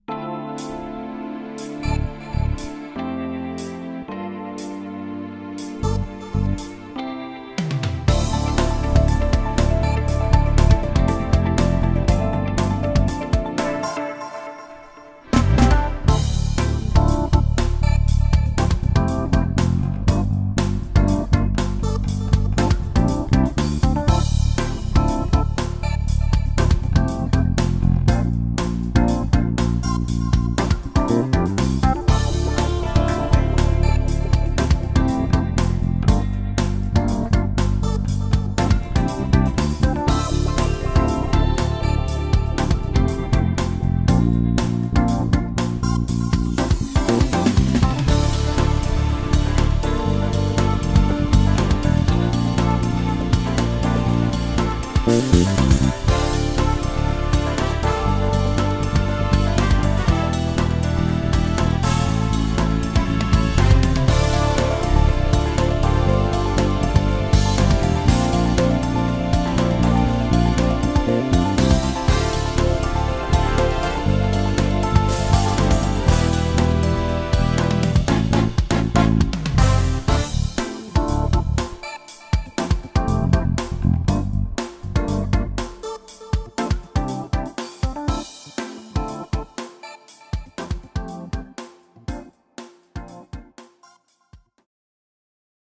Nejvíce se mi líbí zvuk obou snímačů s přepínačem uprostřed. Tedy jakobyhumbucker se singlem vepředu.